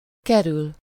Ääntäminen
IPA: /fɥiʁ/